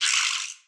ANMLBat_Creature Vocalisation_01.wav